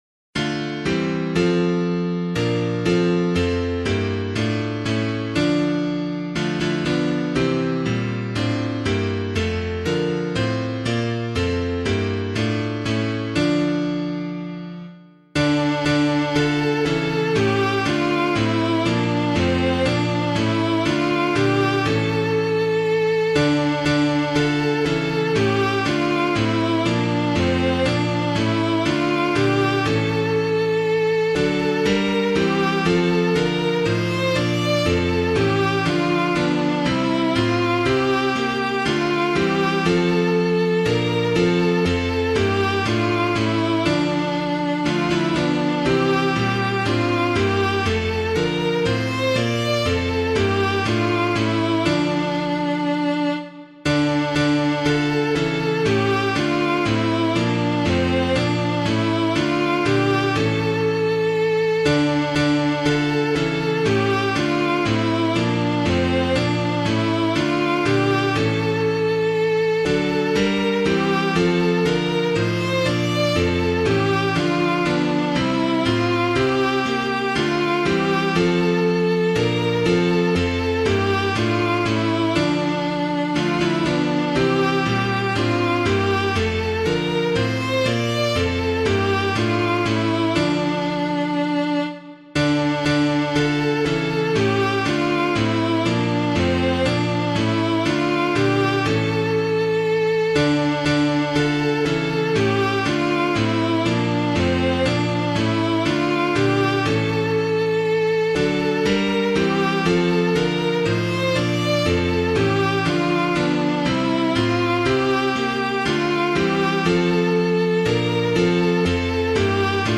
Public domain hymn suitable for Catholic liturgy
God Rest You Merry Gentlemen [anonymous - GOD REST YOU MERRY] - piano.mp3